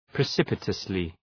Προφορά
{prı’sıpətəslı}